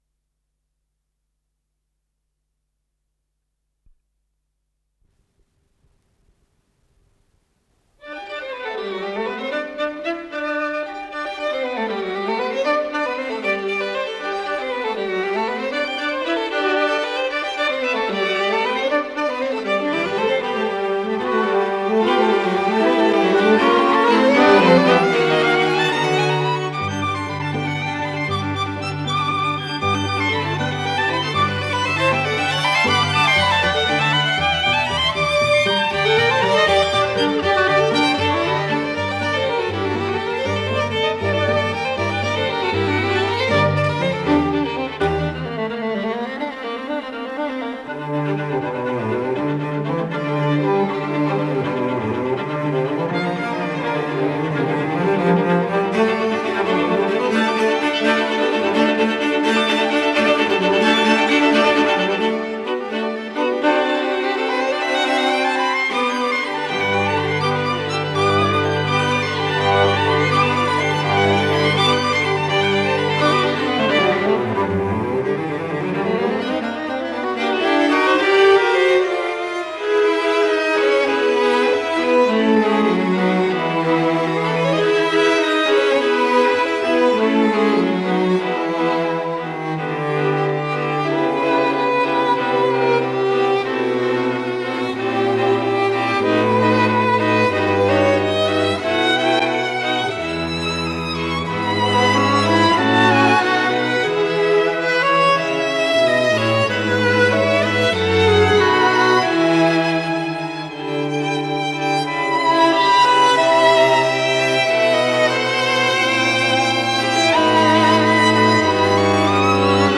для двух скрипок, альта и виолончели, соль мажор
Аллегро мольто